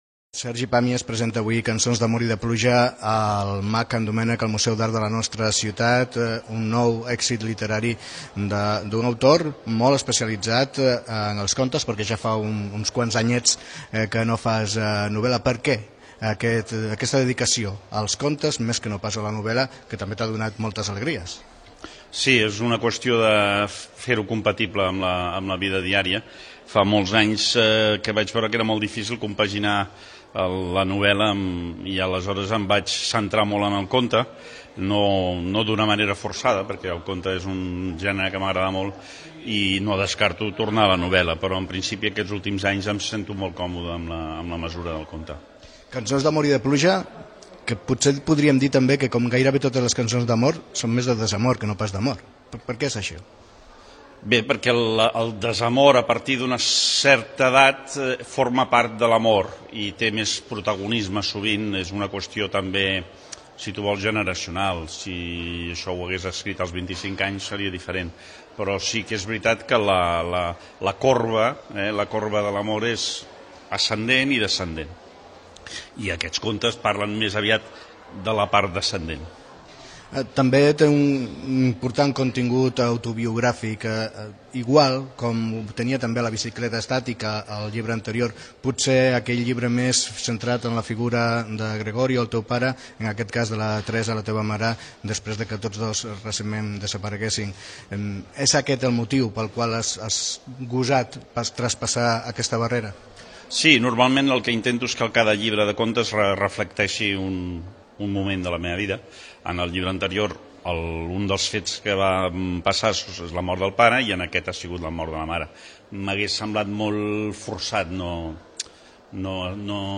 Entrevista a l'escriptor Sergi Pàmies que presentava a Cerdanyola del Vallès el seu llibre de contes "Cançons d'amor i de pluja"